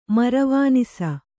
♪ maravānisa